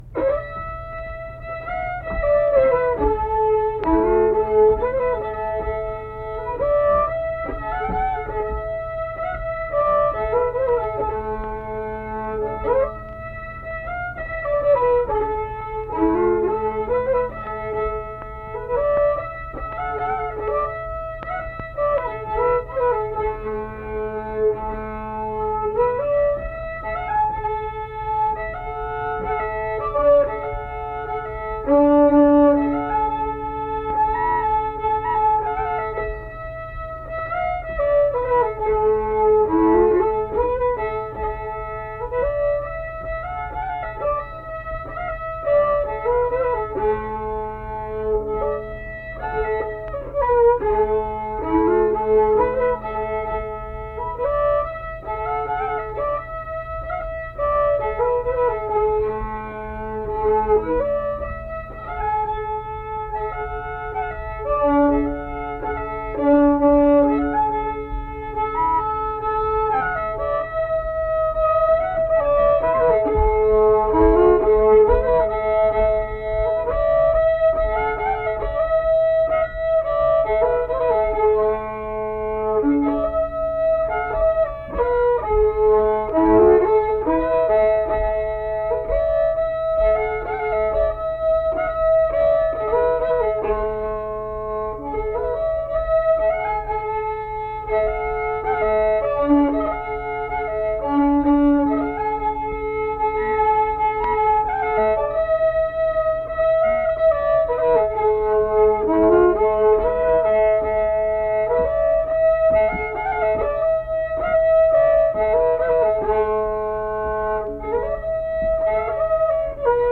Accompanied guitar and unaccompanied fiddle music performance
Guitar accompaniment
Instrumental Music
Fiddle